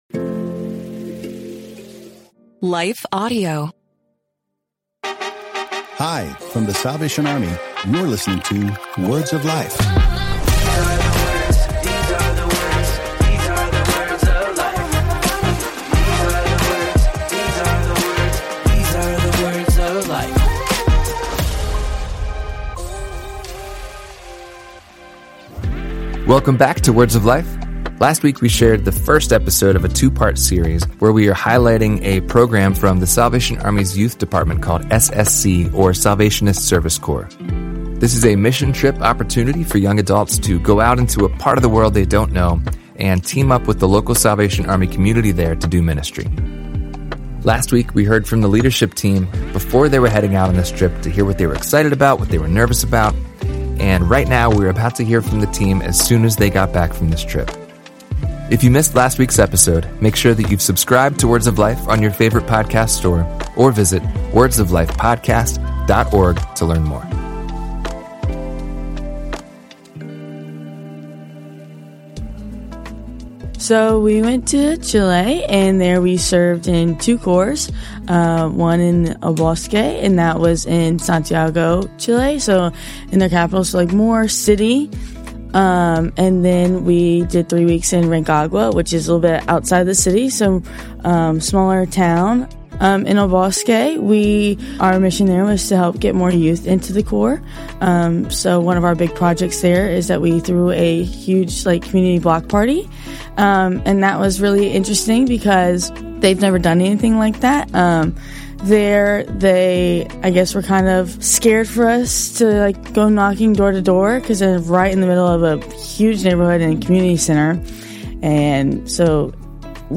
Today, we hear their powerful testimonies after returning from Chile, Scotland, and Alaska. From hosting block parties in Santiago, serving in red-light districts, and bringing life back to a church in Scotland, to prayer walks and Vacation Bible Schools in Alaska, these young missionaries share how God moved in unexpected ways.